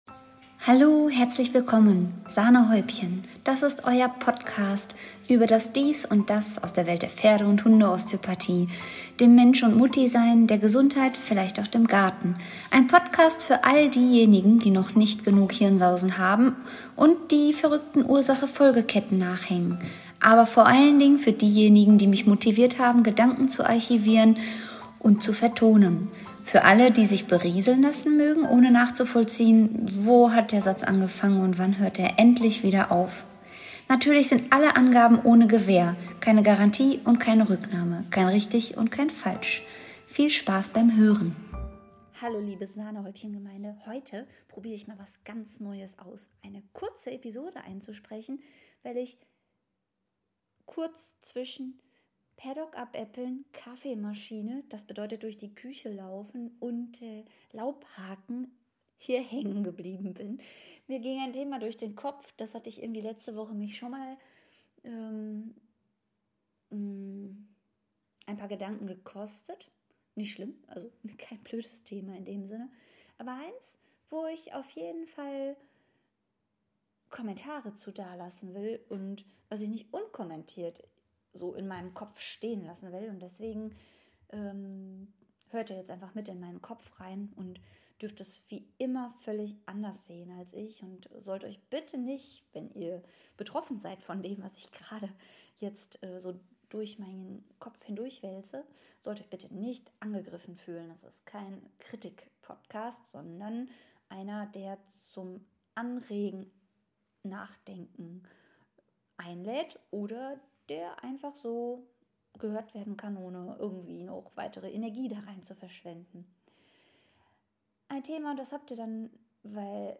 Keine Ahnung warum es immer so ausarten muss wenn ich mit dem Mikro alleine in der Küche rumphilosophiere. Eine Sache, die mich beim Konsumieren der Medien angefangen hat zu kratzen, ist die aus meiner Sicht eindimensionale Darstellung von Erfolgsgeschichten im „Vorher - Nachher“ - Modus.